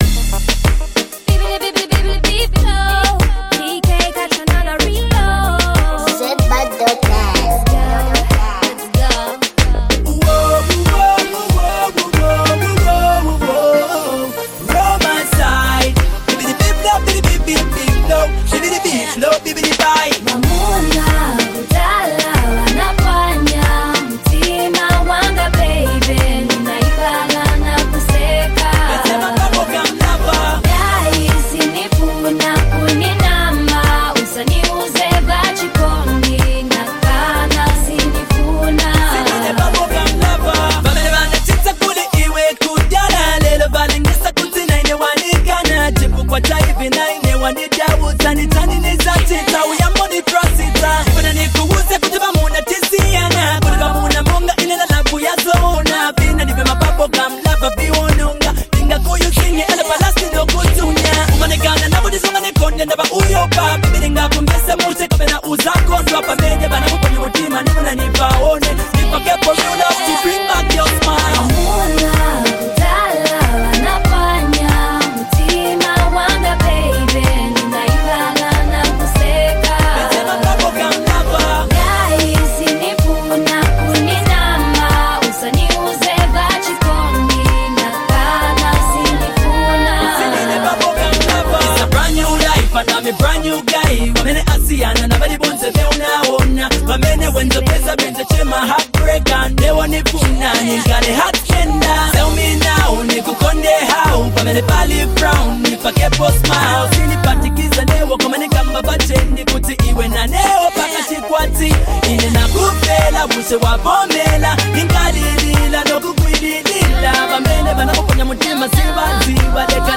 Afrobeats Artist • Lusaka, Zambia